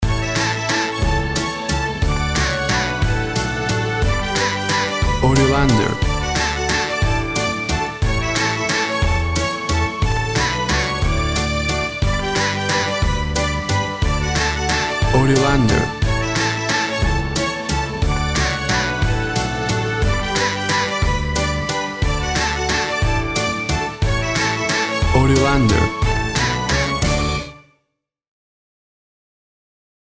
Upbeat, uptempo and exciting!
Tempo (BPM) 175